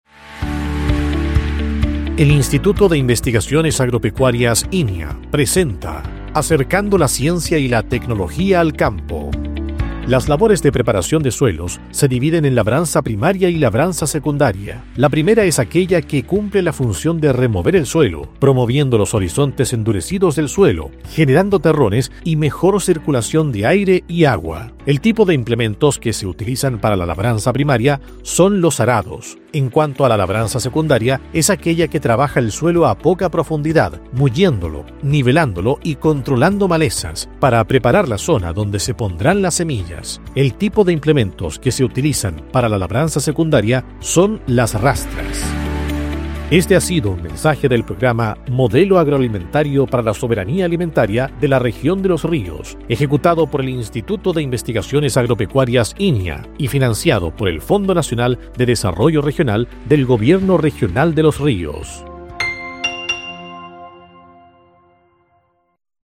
Cápsula Radial INIA Remehue